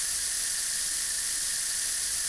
gas_leak_02_loop.wav